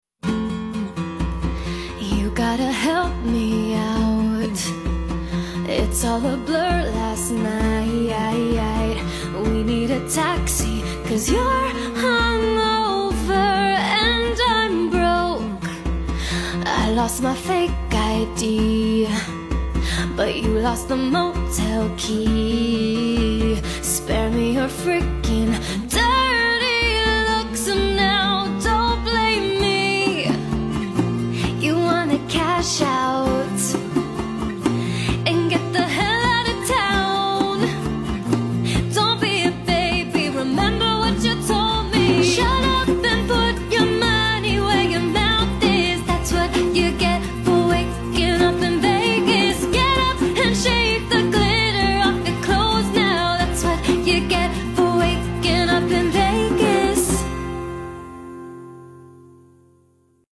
acoustic cover